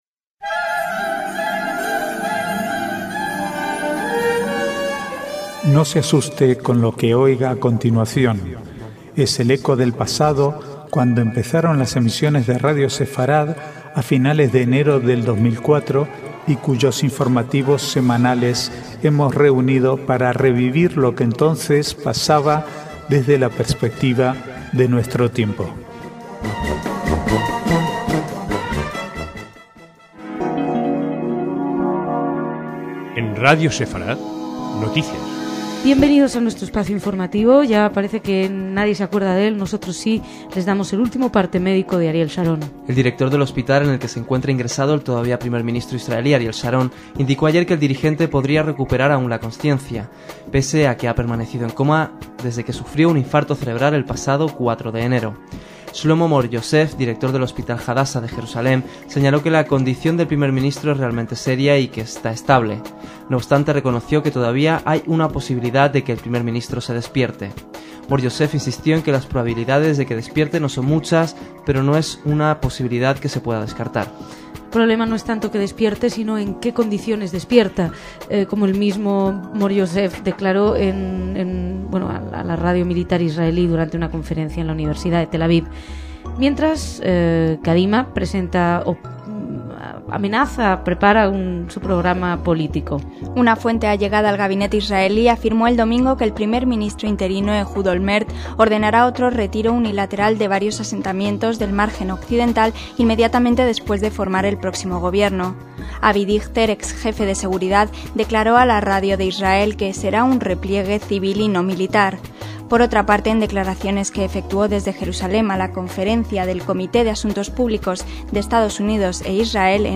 Es el eco del pasado, cuando empezaron las emisiones de Radio Sefarad a finales de enero de 2004 y cuyos informativos semanales hemos reunido para revivir lo que entonces pasaba desde la perspectiva de nuestro tiempo.